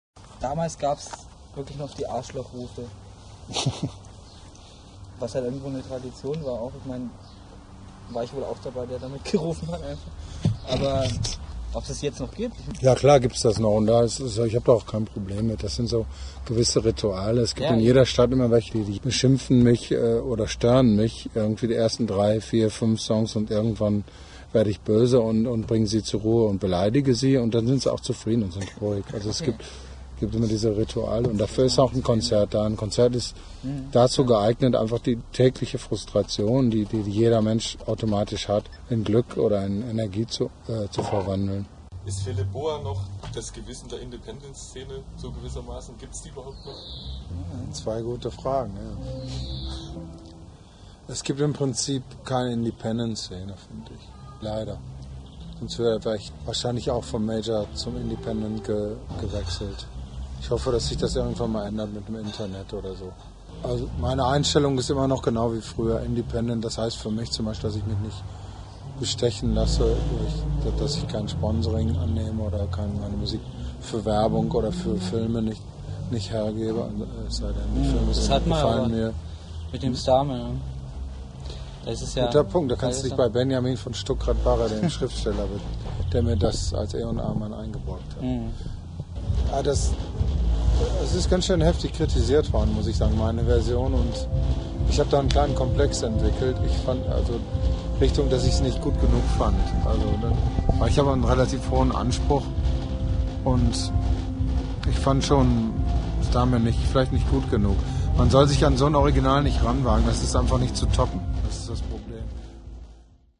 MP3 - INTERVIEW- AUSSCHNITT